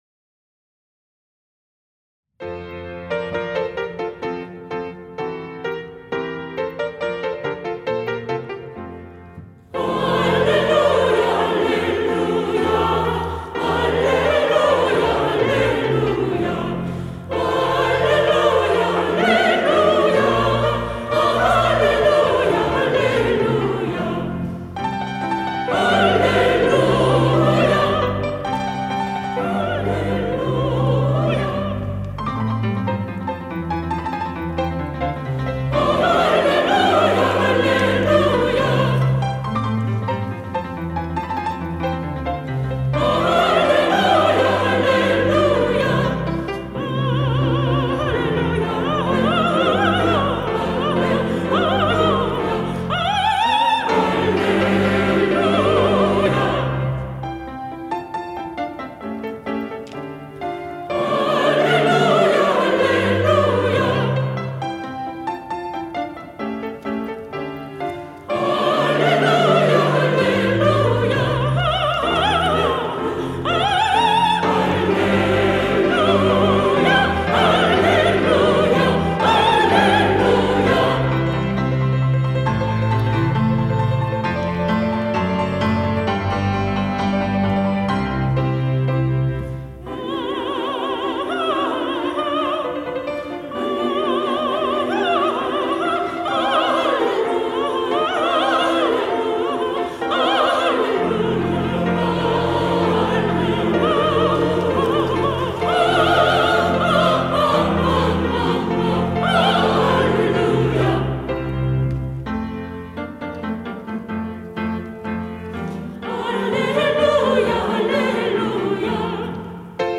시온(주일1부) - 알렐루야
찬양대